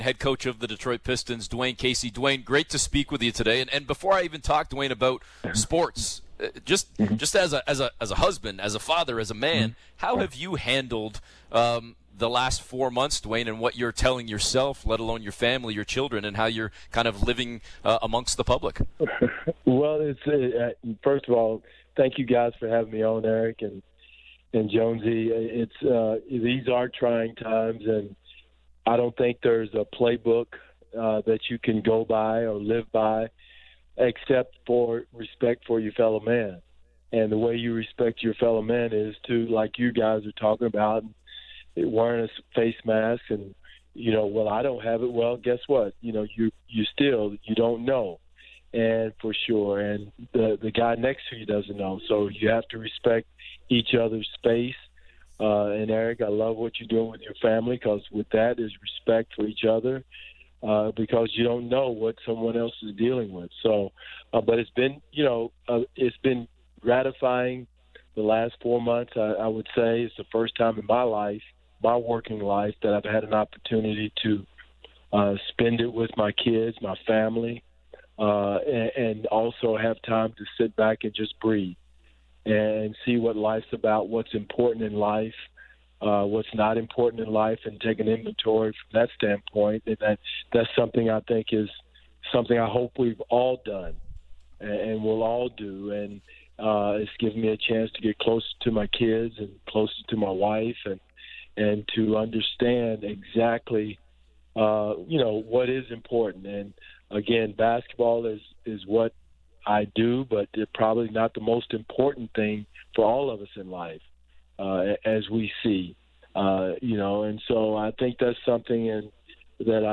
Casey also shared his thoughts on the NBA playoff format, how he has handled a life social distancing these past several months, and how he views the recent protests of racial injustice and calls for social change. Listen to the full interview below.